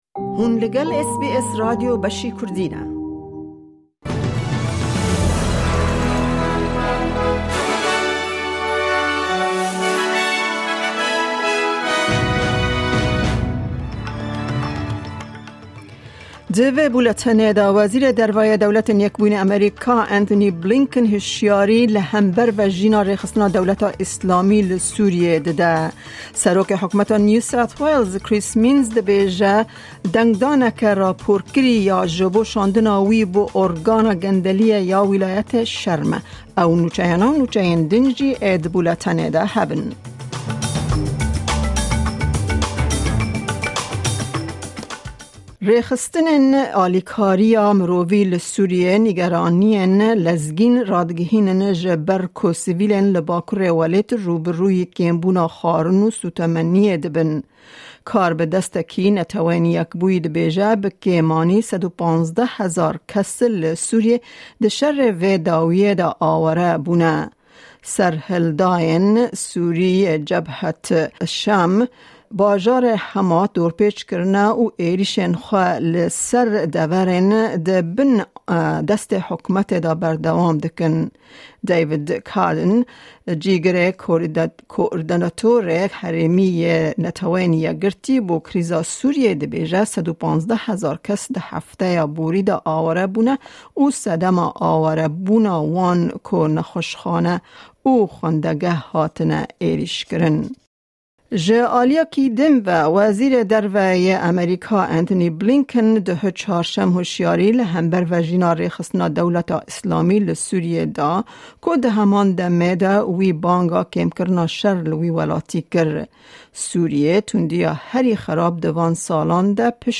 Bûletena nûçeyên roja Pêncşemê 5î Kanûna 2024